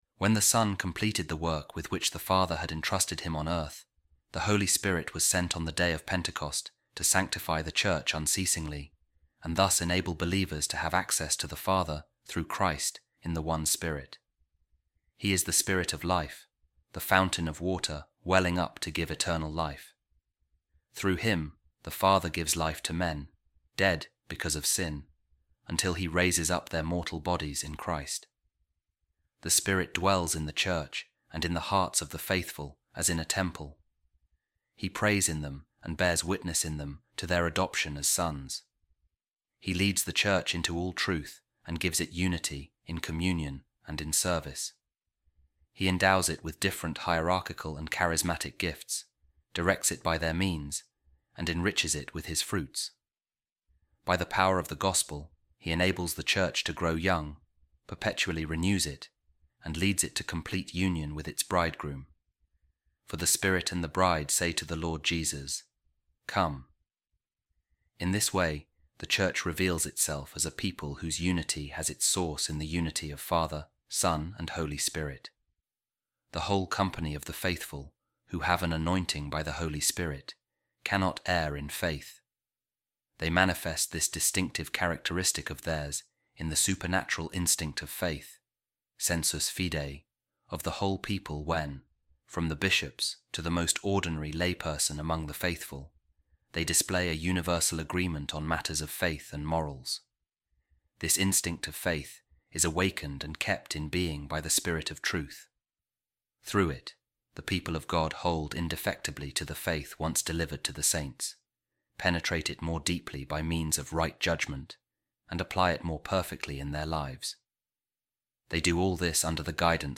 A Reading From The Constitution Of The Second Vatican Council On The Church | The Mission Of The Holy Spirit